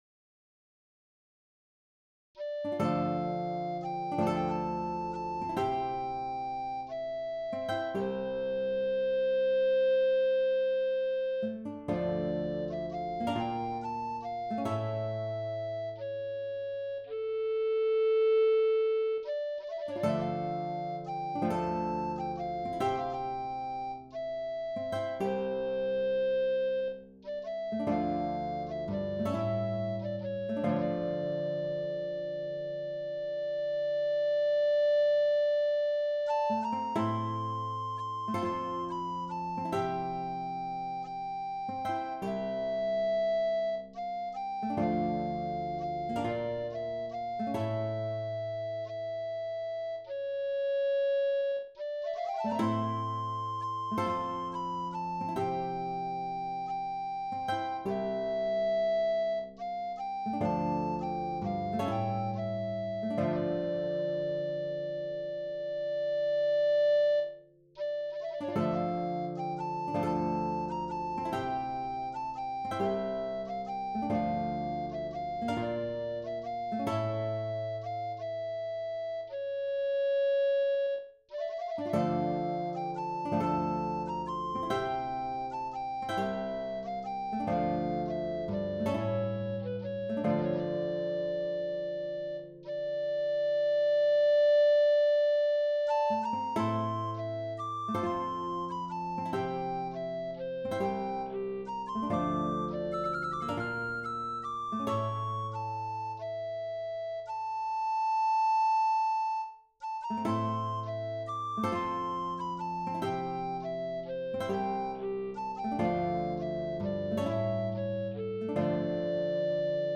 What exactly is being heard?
Voicing/Instrumentation: SATB , Recorder